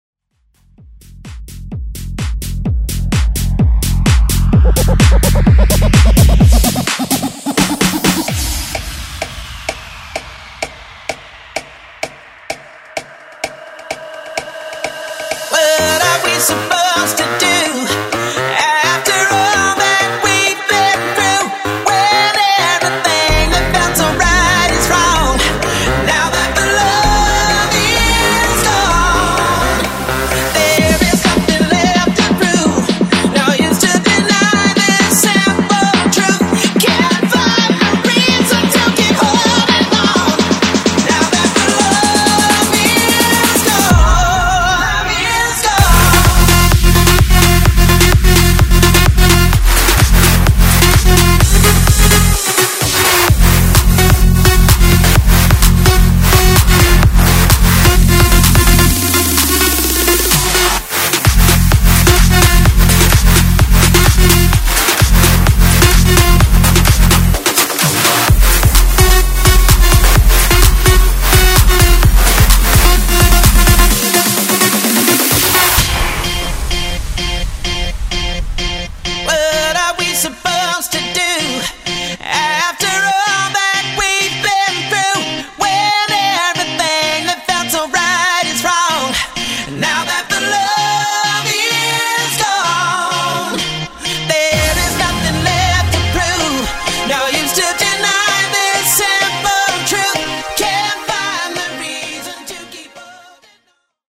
Genres: EDM , MASHUPS , TOP40
Clean BPM: 128 Time